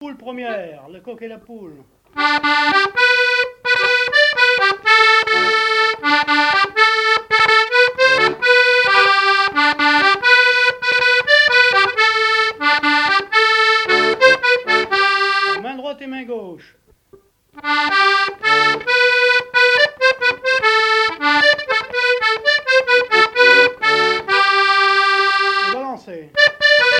danse : quadrille : poule
Pièce musicale inédite